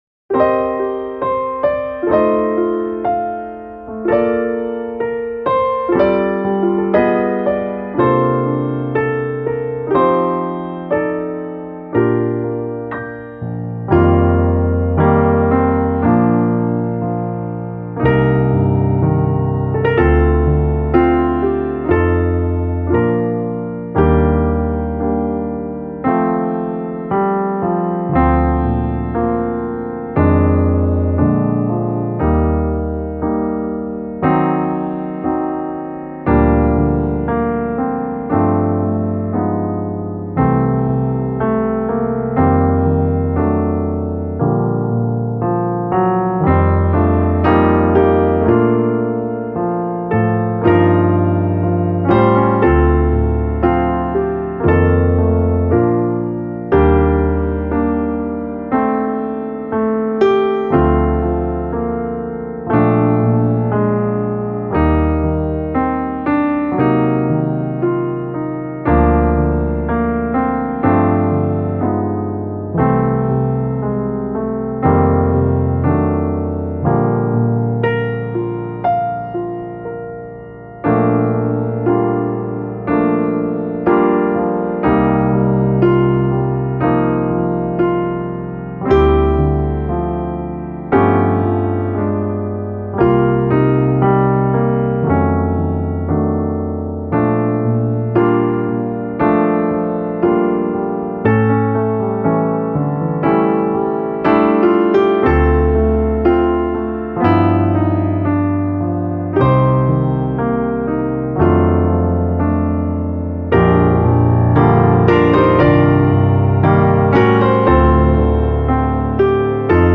Over The Rainbow (Gesang solo)
Hier findet ihr Text, Begleitstimmen in mittlerer und tiefer Lage, ein Demo und ein paar Tipps von mir, wie ihr euch diesen Song erarbeiten könnt.